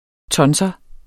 Udtale [ ˈtʌnsʌ ]